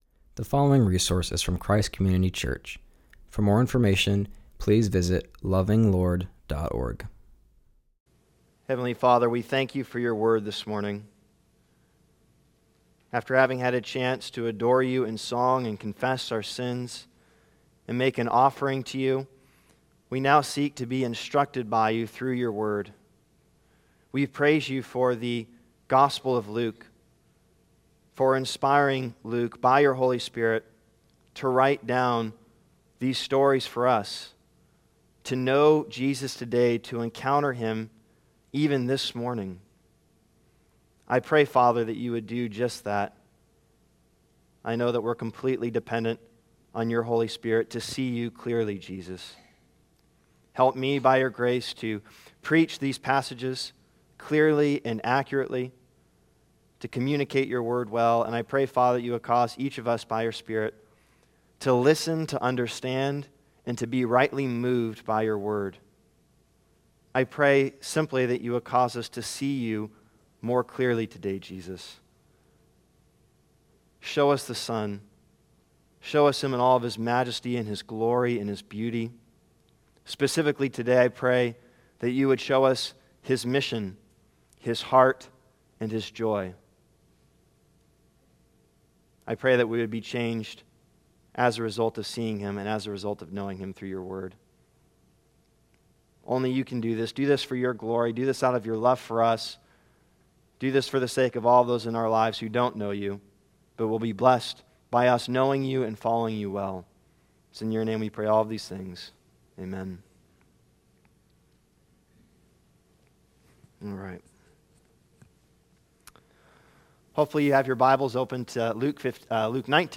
continues our study in Luke by preaching on Luke 19:1-10, 15:11-32